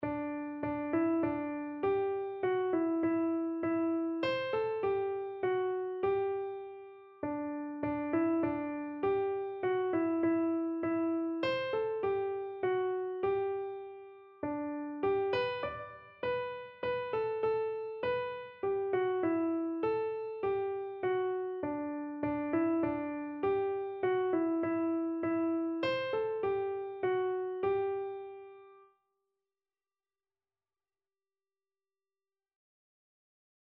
Christian
Free Sheet music for Keyboard (Melody and Chords)
3/4 (View more 3/4 Music)
Keyboard  (View more Intermediate Keyboard Music)
Classical (View more Classical Keyboard Music)